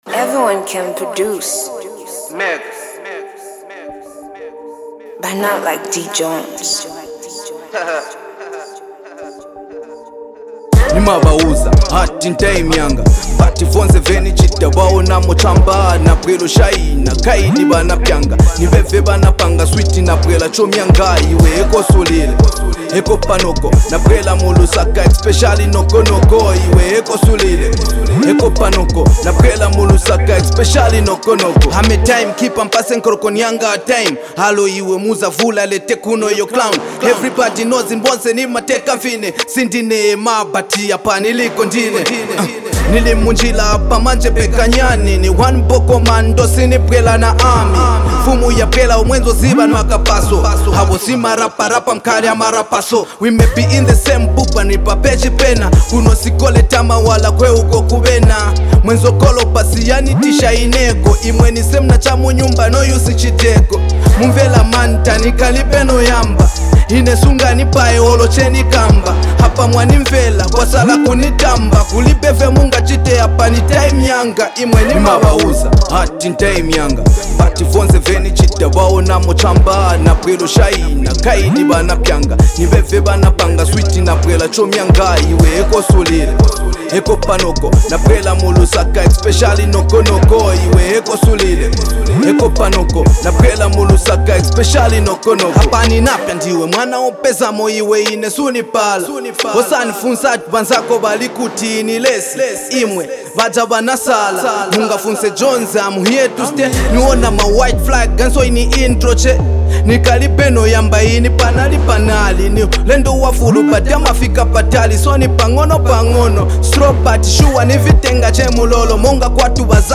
and is a remarkable hip-hop piece.